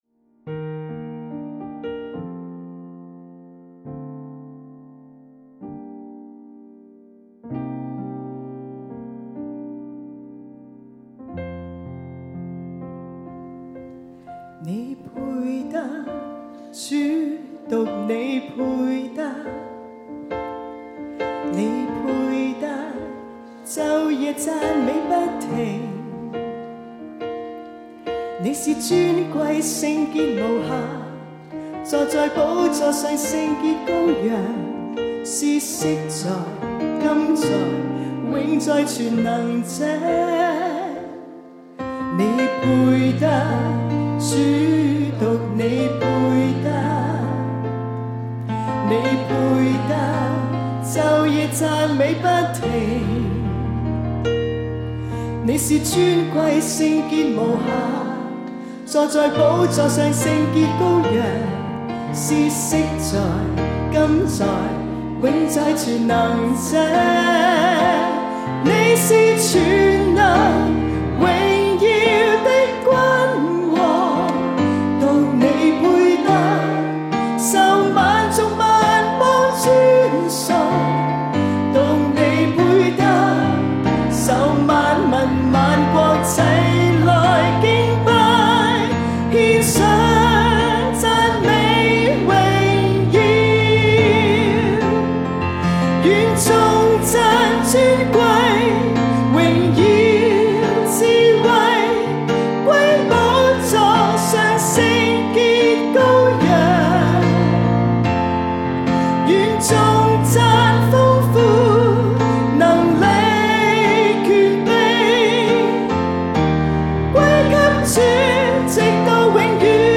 Key Bb, Tempo 68
現場敬拜